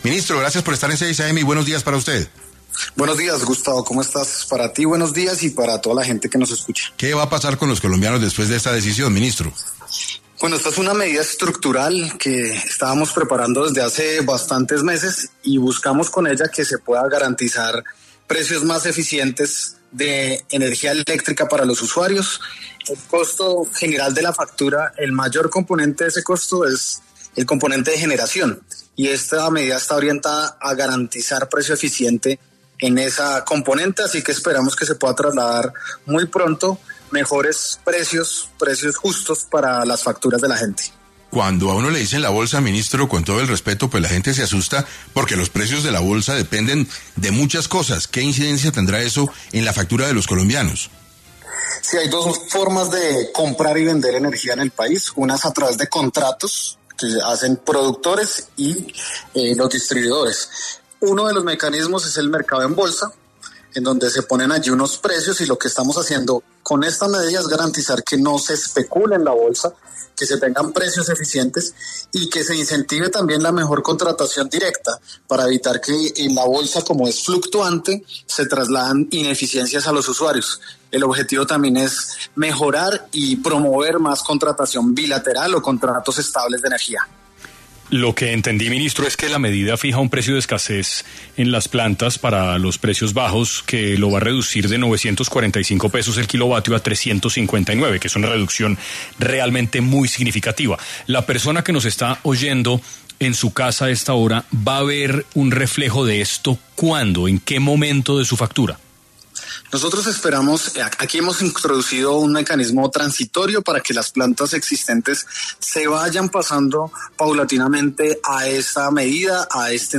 En 6AM de Caracol radio, estuvo Andrés Camacho, ministro de Minas y Energía, para hablar sobre lo que representa para los colombianos la decisión del Gobierno de fijar un tope tarifario a los precios de la energía en la bolsa.